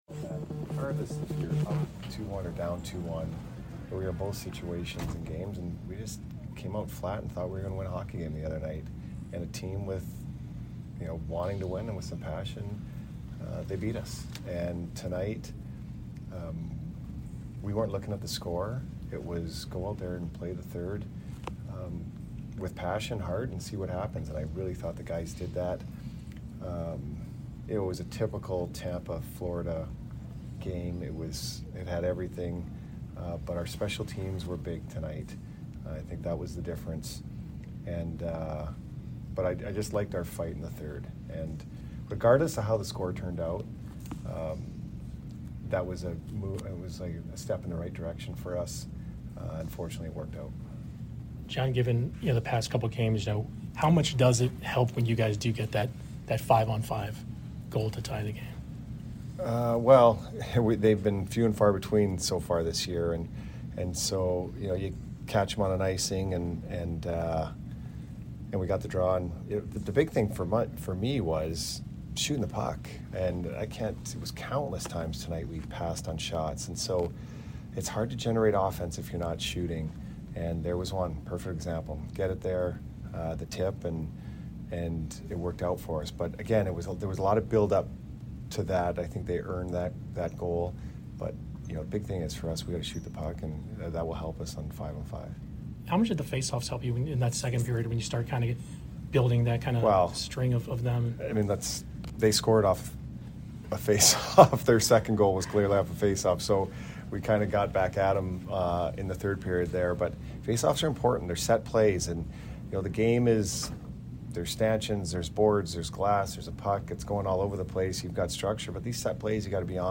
Head Coach Jon Cooper Post Game 10/21/22 @ FLA